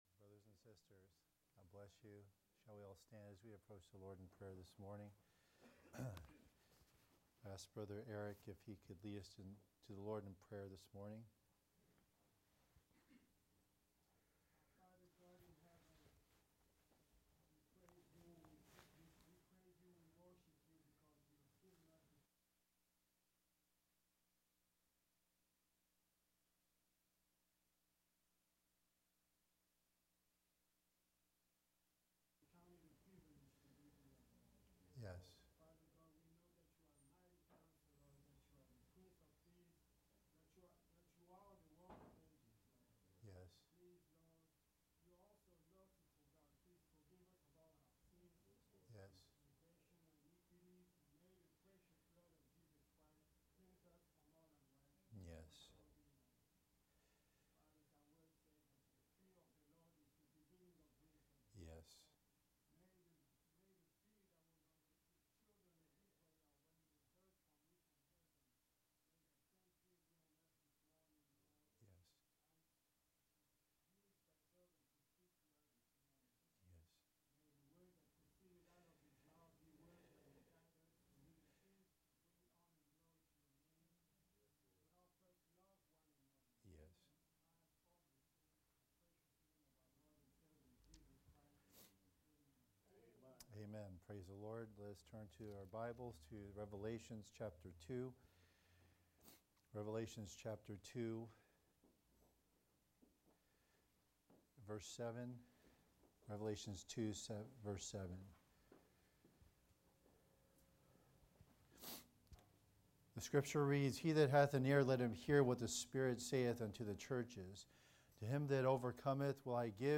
The Character of Christ -Part 20 (Discerning the Spirits) – Sunday school